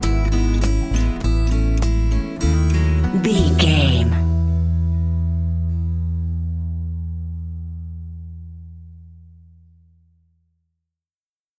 Ionian/Major
drums
acoustic guitar
piano
violin
electric guitar